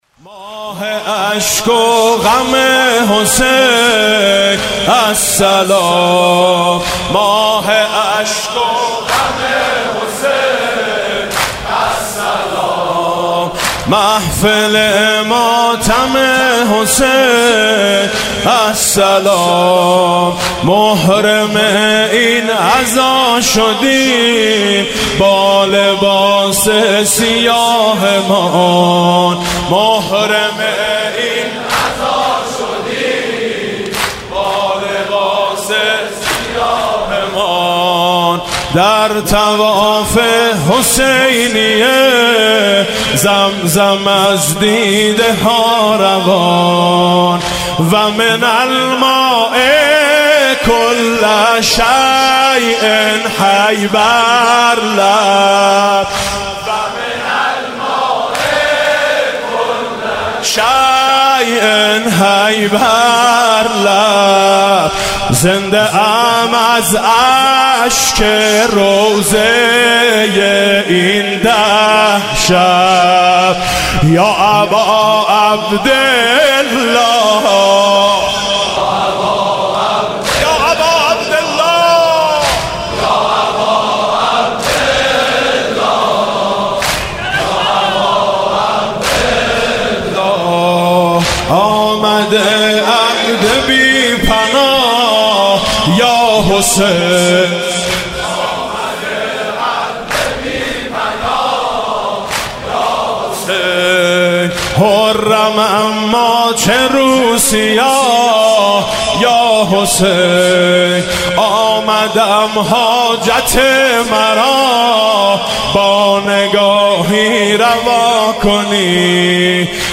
صوت مراسم شب اول محرم 1438 هیئت میثاق با شهدا ذیلاً می‌آید:
واحد تند: زنده‌ام از اشک روضه‌ی این ده شب | برادر میثم مطیعی